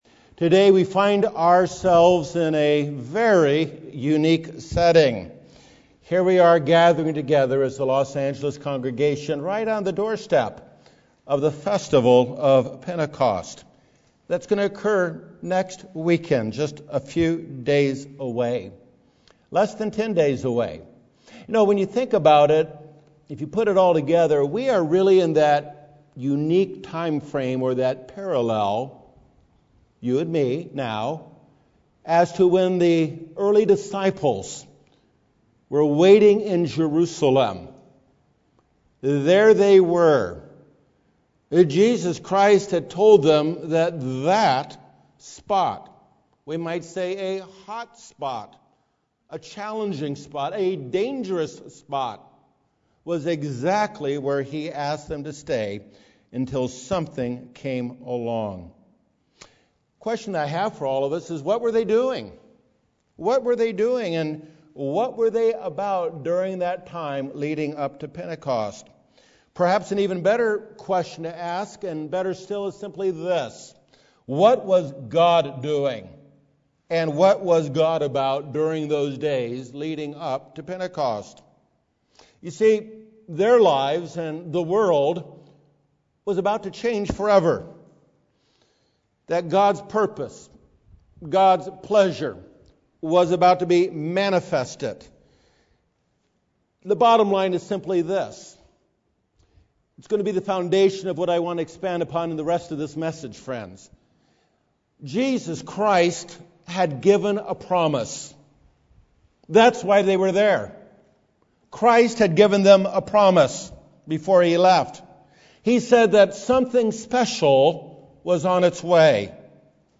UCG Sermon Transcript